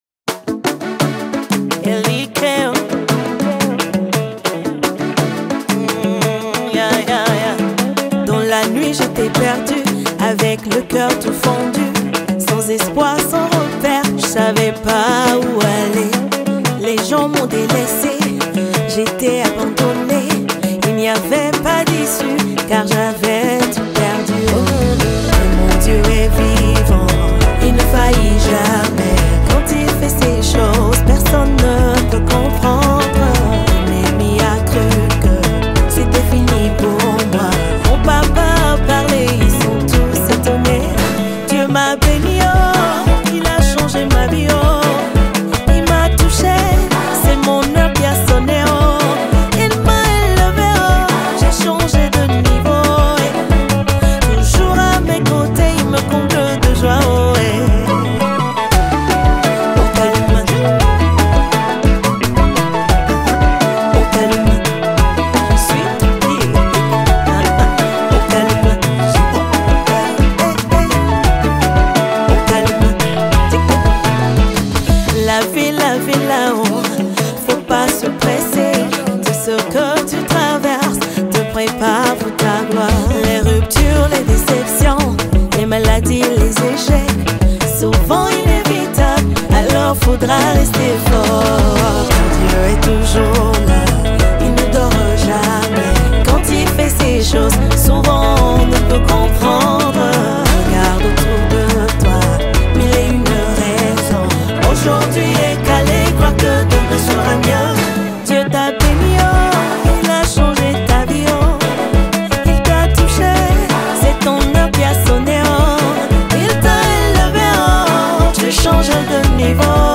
Universal Gospel